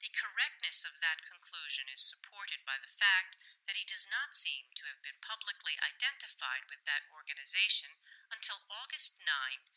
highpass_0.2.wav